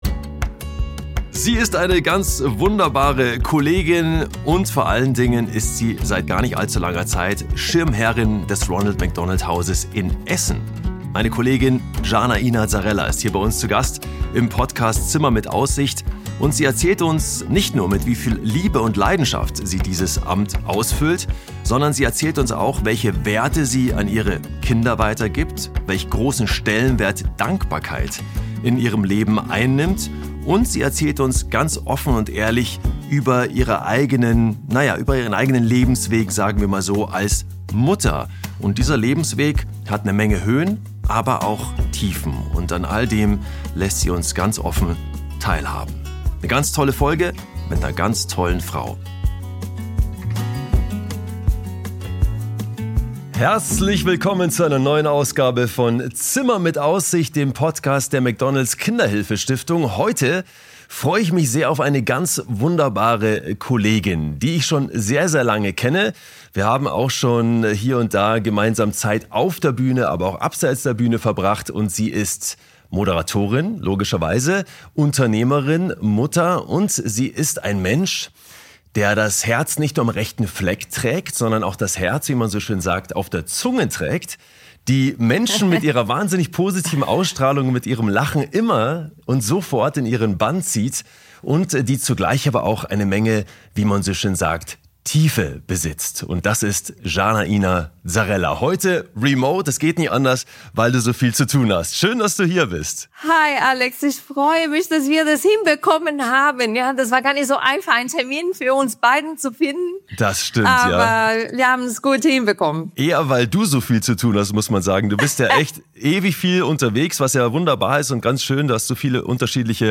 Darum geht’s in dieser Folge: In dieser Folge spricht Moderatorin und Schirmherrin Jana Ina Zarrella offen und mit ihrer gewohnt warmherzigen Art über das, was sie wirklich prägt.